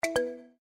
notify.mp3